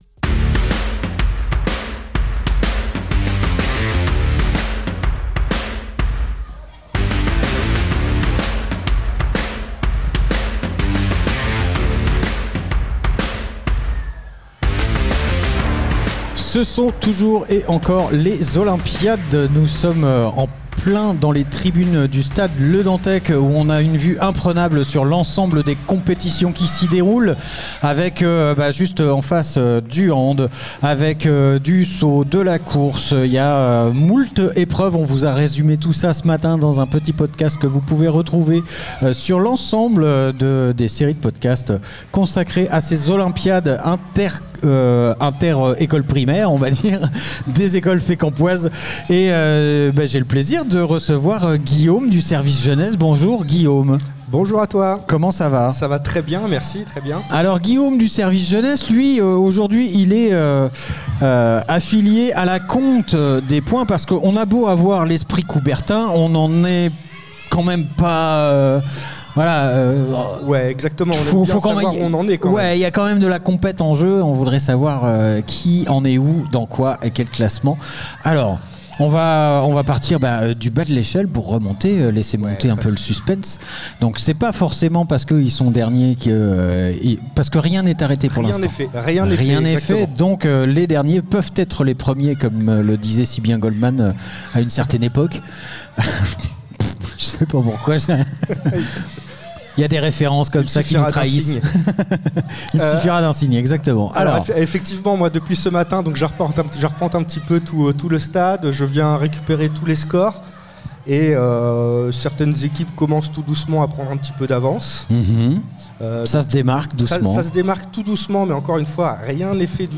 Olympiades des écoles Interview épreuves les olympiades des écoles